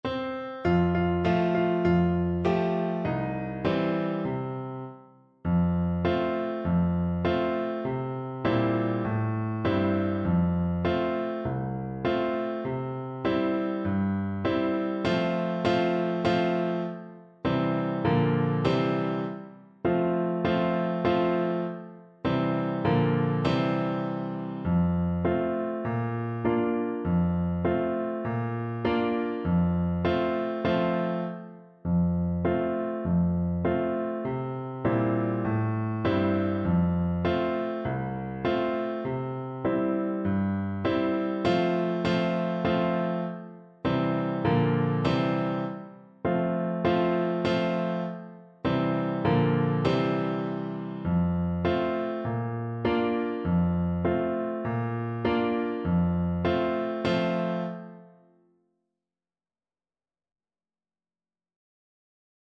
Flute
F major (Sounding Pitch) (View more F major Music for Flute )
4/4 (View more 4/4 Music)
Moderato
Traditional (View more Traditional Flute Music)
world (View more world Flute Music)
Zairian